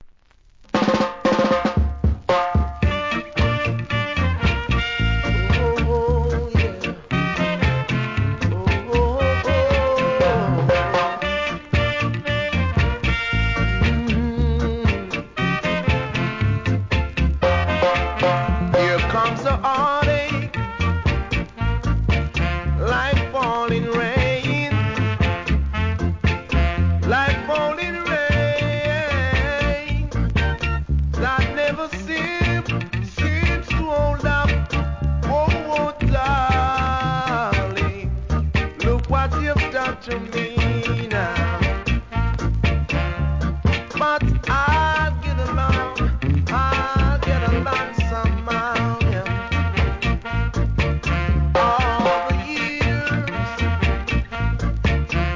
REGGAE
1973年、うねるシンセでHIGH RANKIN'!!